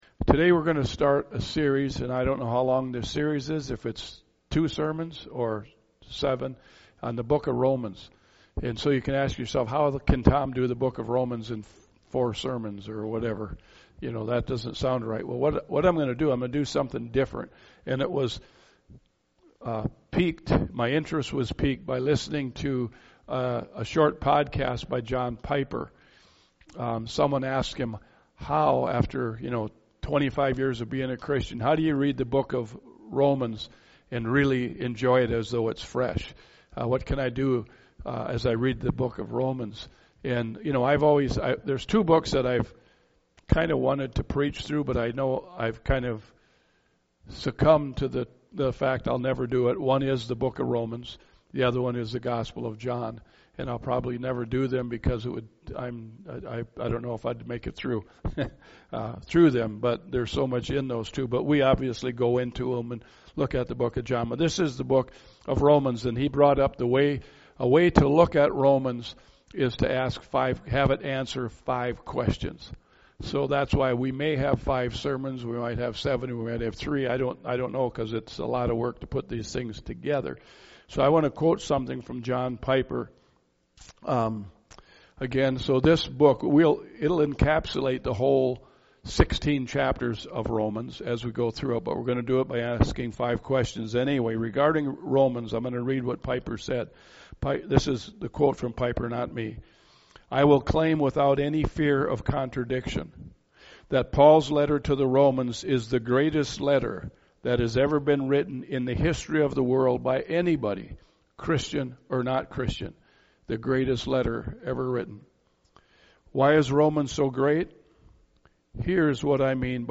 » Sermon Audio